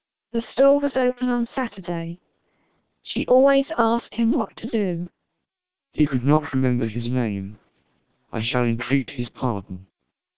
Experts haven't found significant difference in sounding between SPR 1200 and MELPe 1200 vocoders.
You can play and listen short samples of the source speech as well as the speech processed by these vocoders for any of 20 languages, using links in the table below.